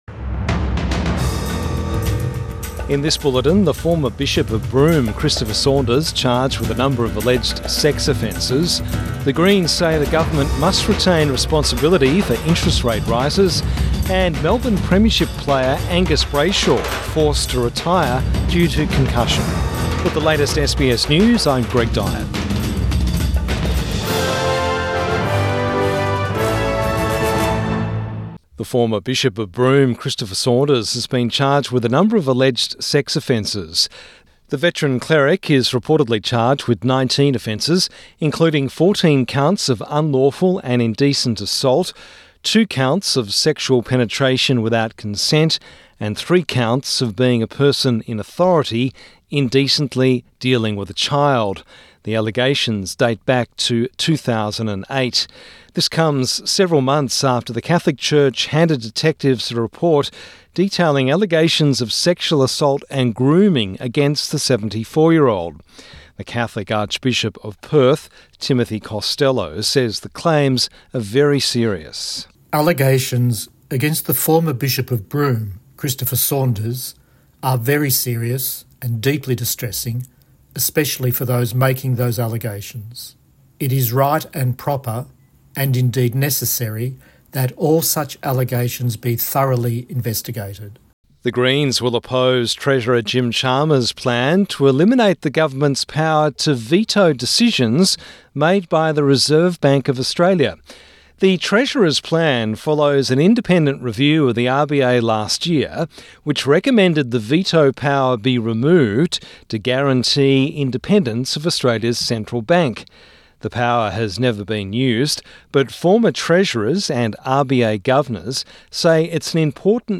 Evening News Bulletin 22 February 2024